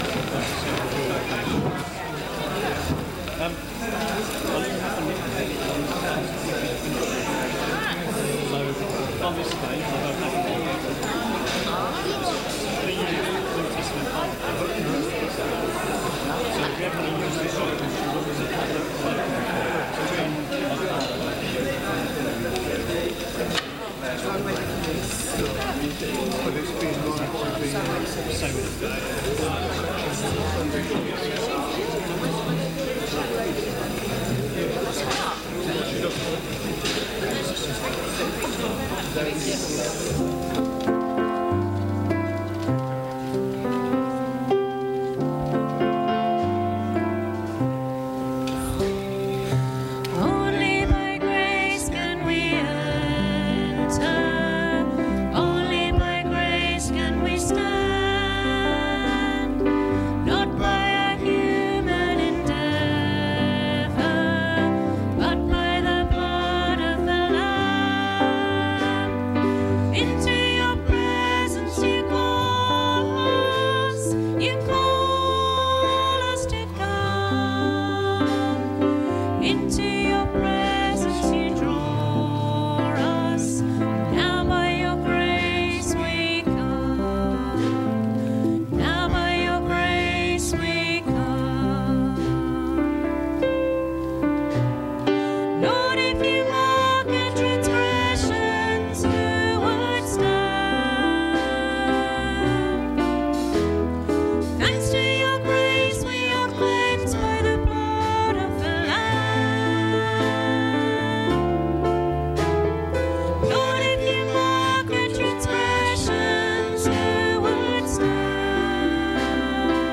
Family service - Sittingbourne Baptist Church
Join us for a service about forgiveness, suitable for the whole family, led by our children's team.